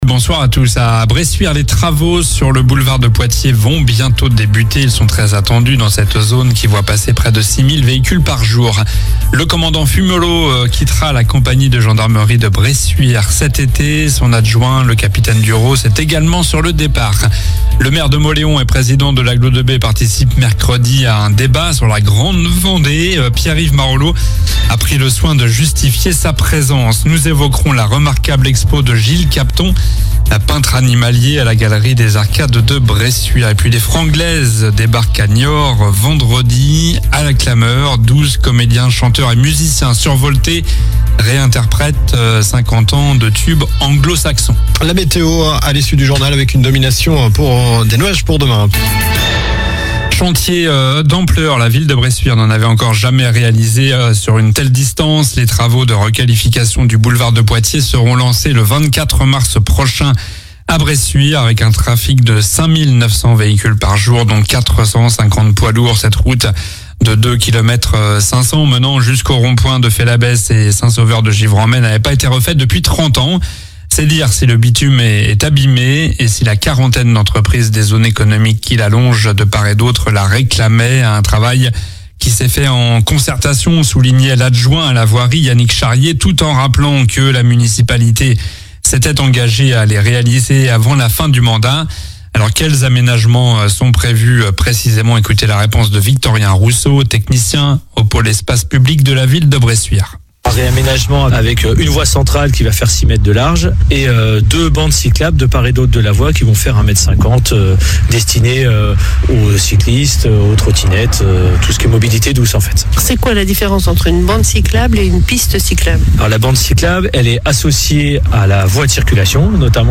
Journal du mardi 11 mars (soir)